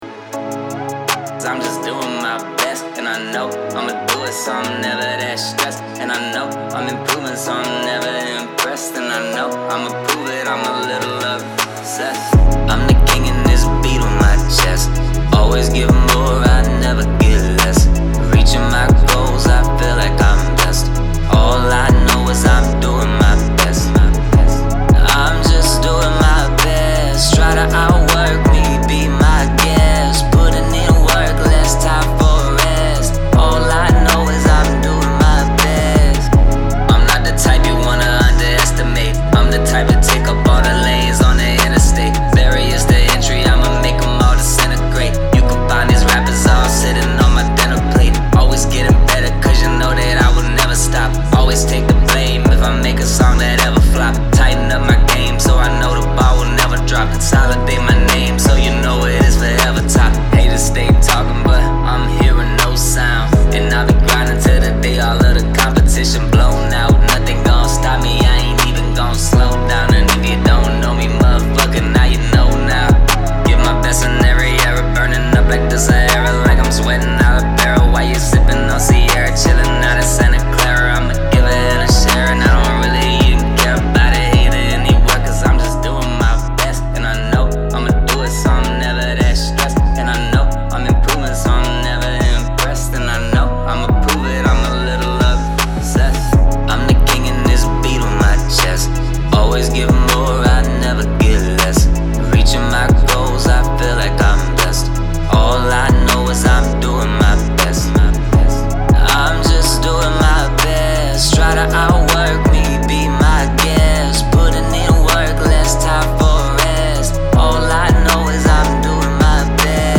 Hip-Hop Vocal Mix Help
I am trying to get better at mixing, but I am a noob.
Right now, I am trying to get a Drake-like mix.